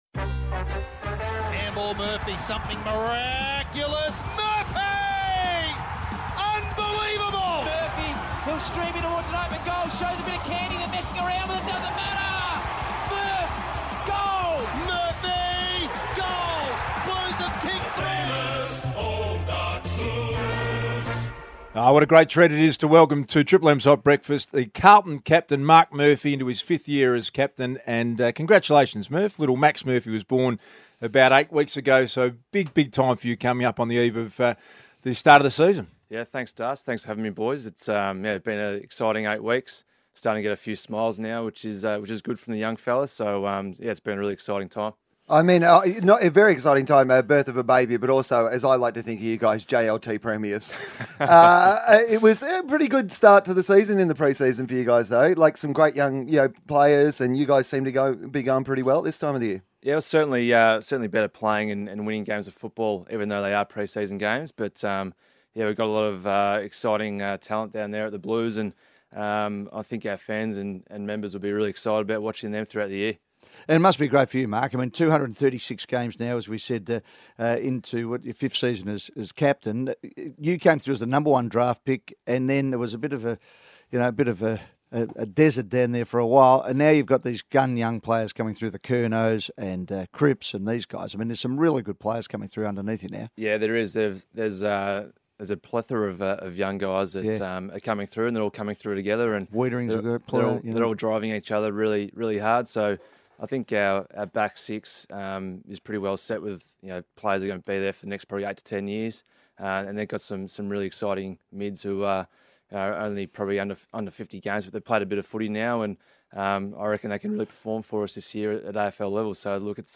Carlton skipper Marc Murphy joins the Triple M Hot Breakfast studio during the 2018 AFL Captains' Day.